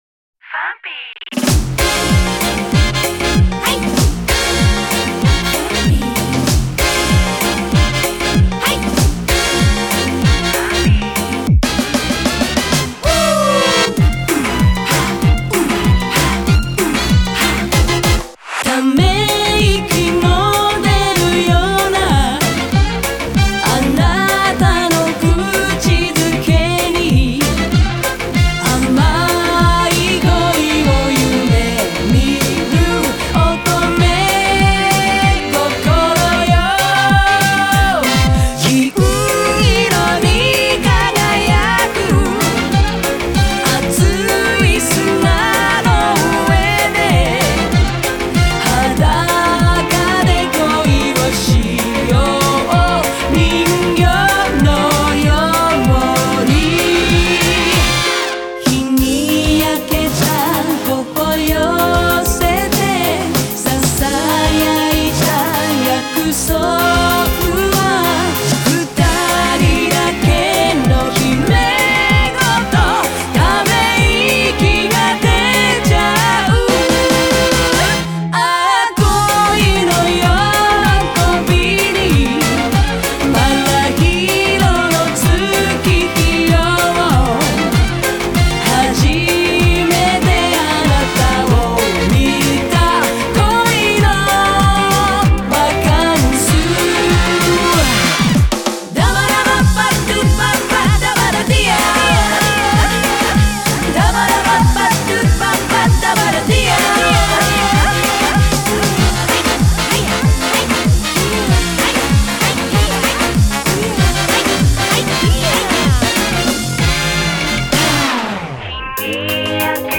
аранжировка направлена на современный лад.